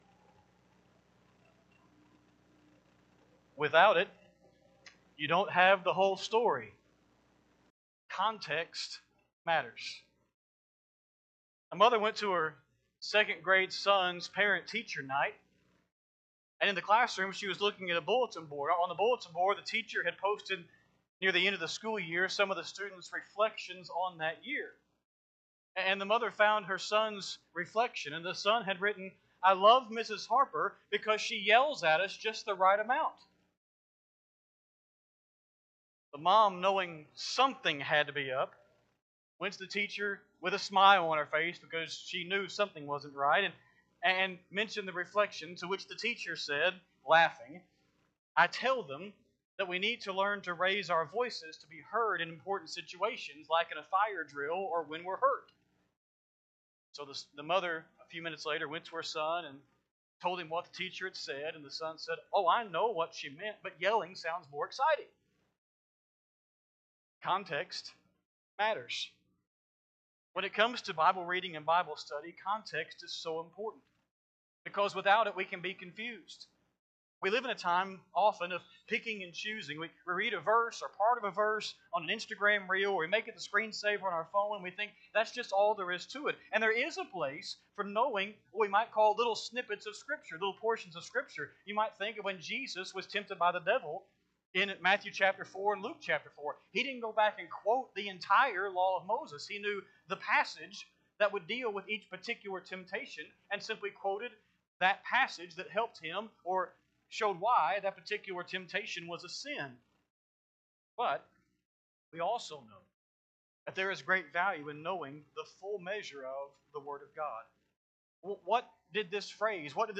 Sunday PM Sermon
7-6-25-PM-Sermon.mp3